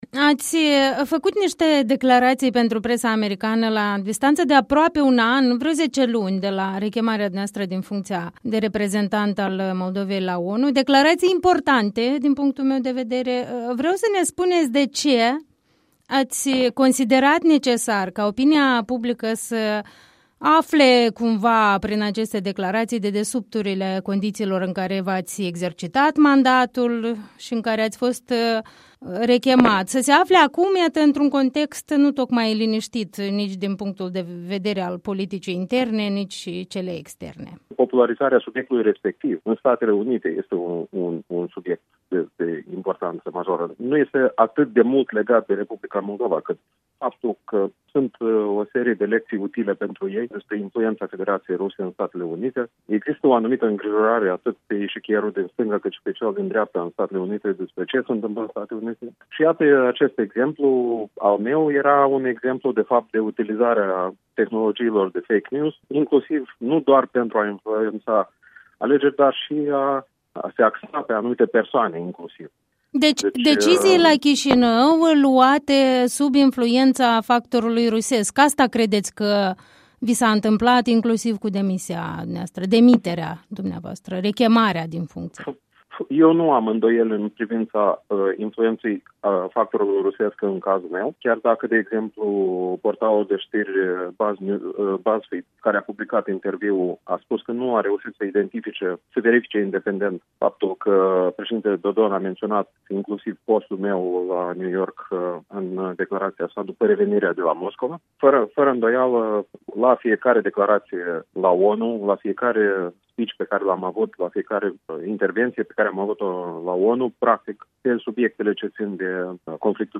Un interviu cu fostul diplomat ambasador al Moldovei pe lîngă Națiunile Unite.